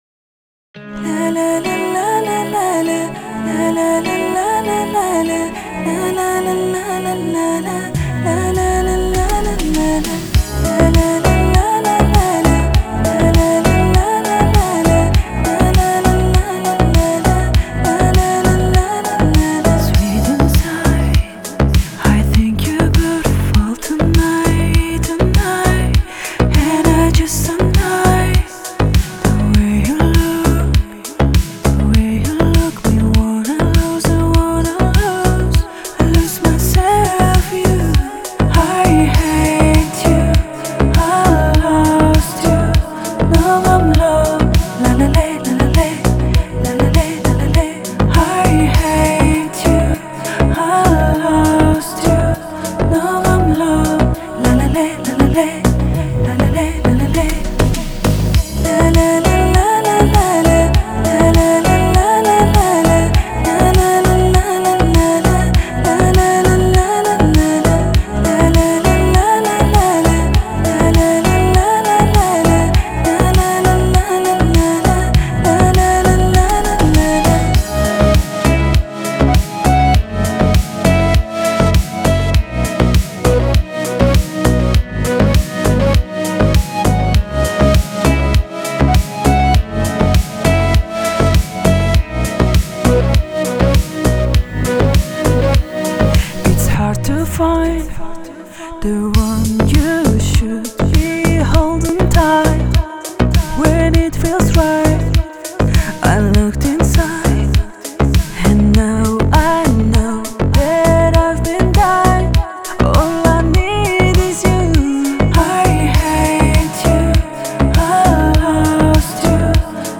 Трек размещён в разделе Зарубежная музыка / Танцевальная.